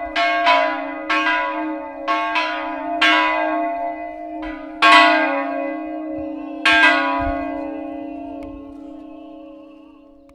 • church bells sound.wav
church_bells_sound_Ggl.wav